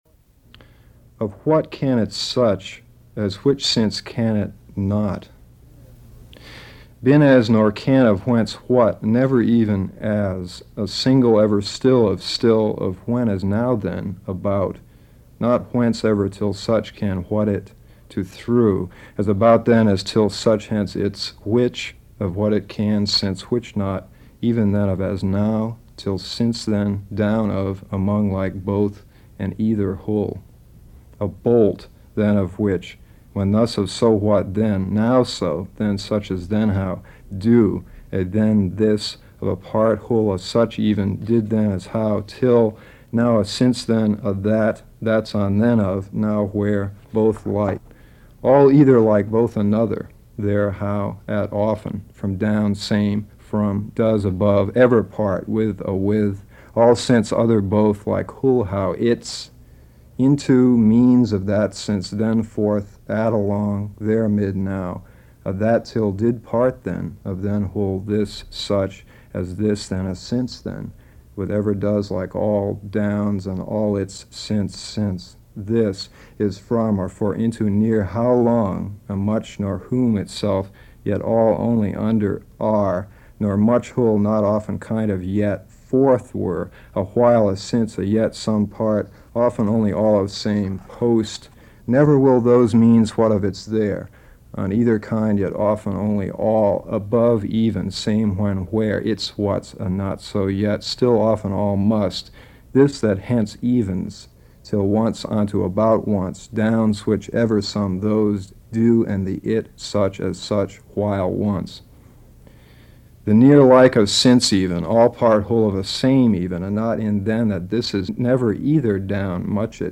at UCONN in Storrs